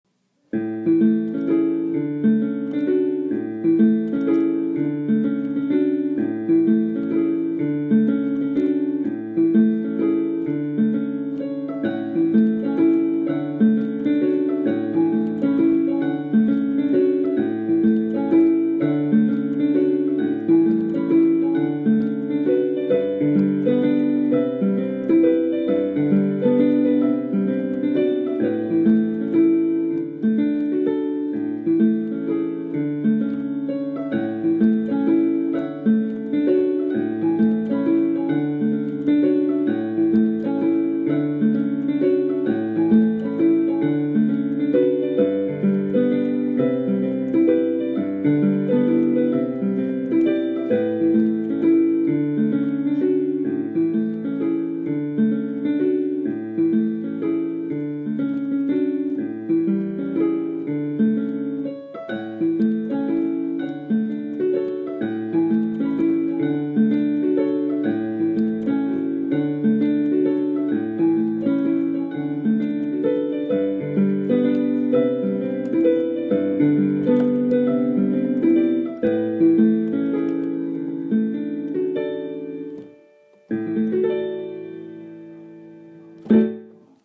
】【姉がアホすぎてヤバすぎる。】【ギャグ声劇】 (姉) お名前 (弟) お名前 nanaRepeat